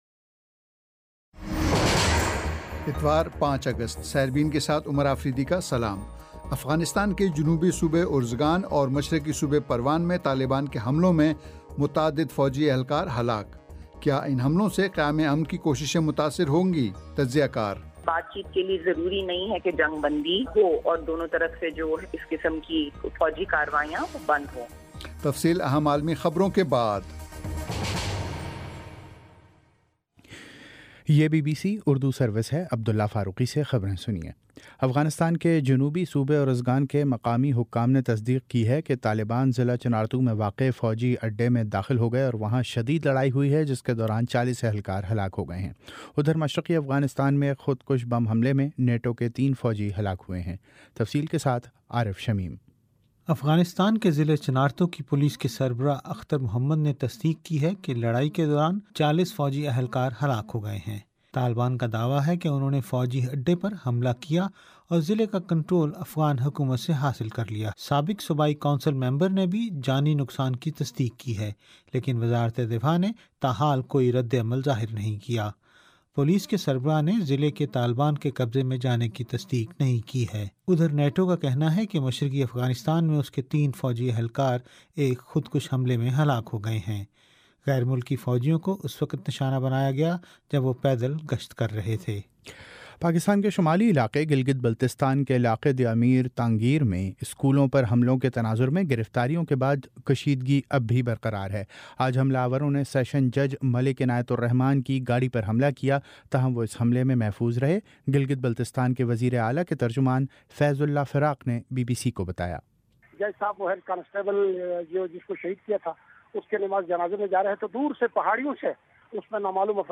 اتوار 05 اگست کا سیربین ریڈیو پروگرام